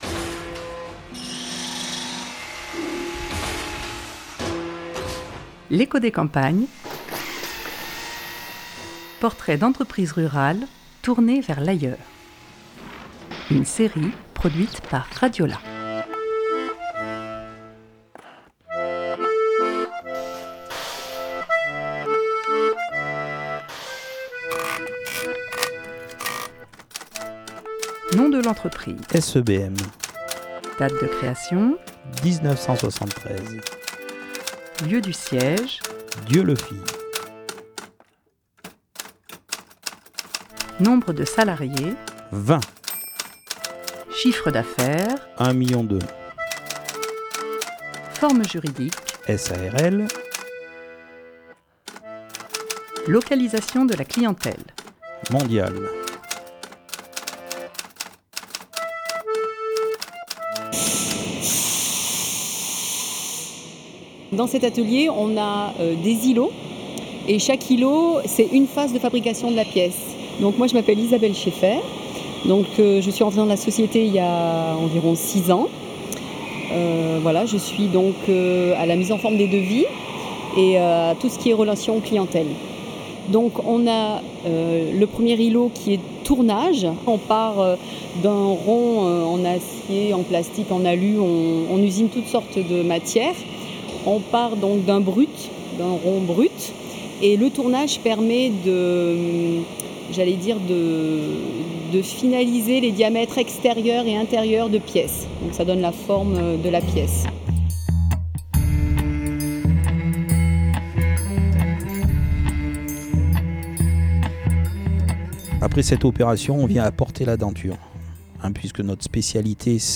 6 avril 2021 15:51 | eco des campagnes, podcasts maison, reportage, territoire